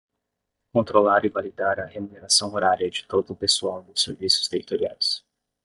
Pronounced as (IPA) /va.liˈda(ʁ)/